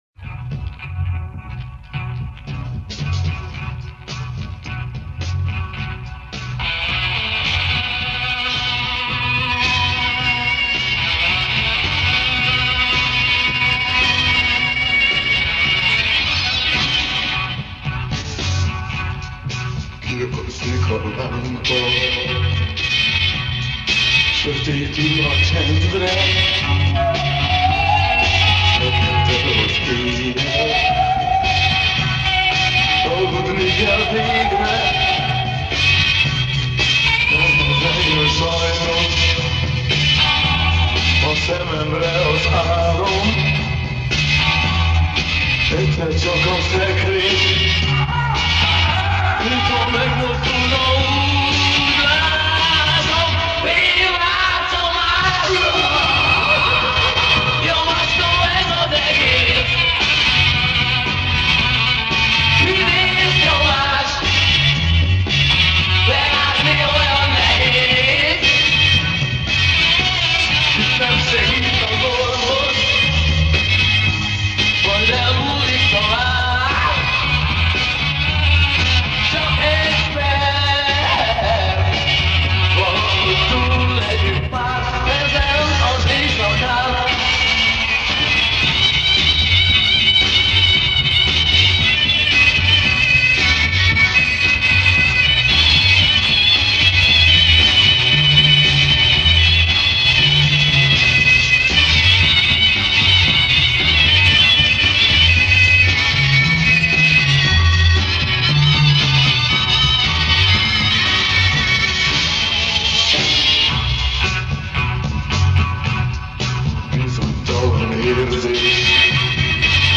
Заставка